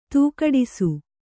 ♪ tūkaḍisu